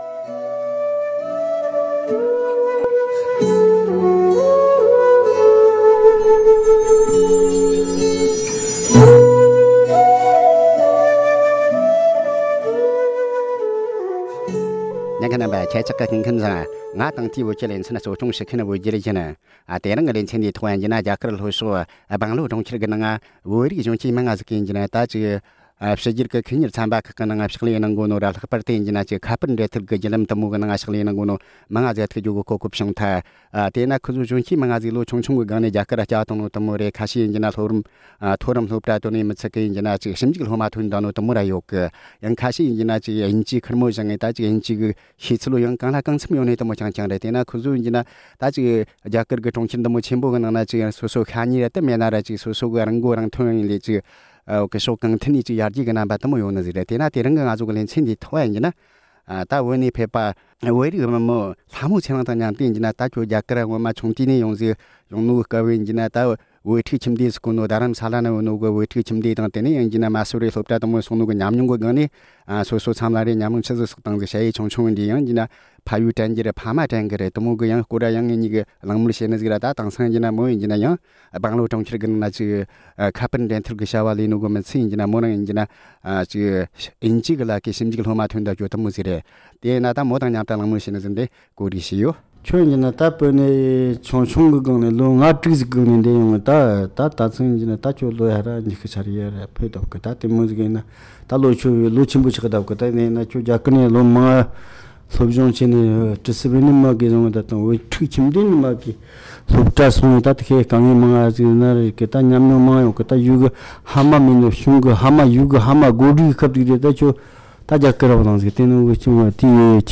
བོད་རིགས་ན་གཞོན་བུད་མེད་ཅིག་དང་མཉམ་དུ་ཁོ་མོ་བཙན་བྱོལ་དུ་སློབ་གྲྭ་འགྲིམ་པའི་རྒྱུད་རིམ་ཐད་གླེང་མོལ།